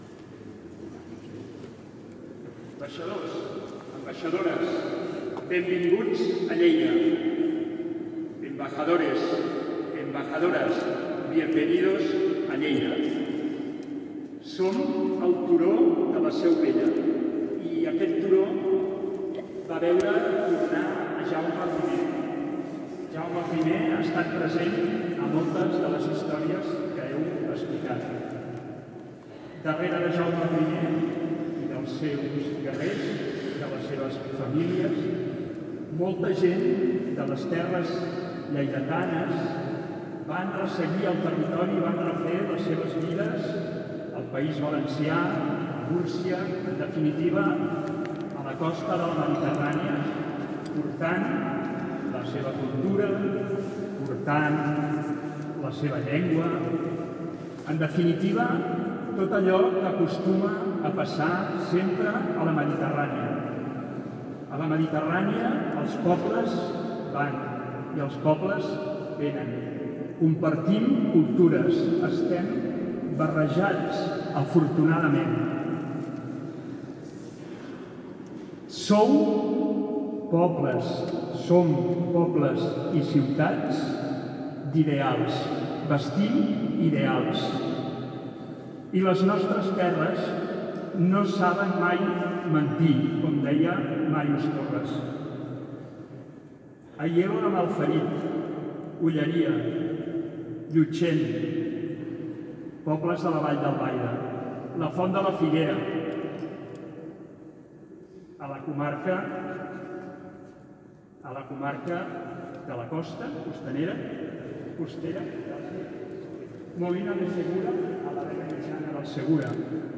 tall-de-veu-jaume-rutllant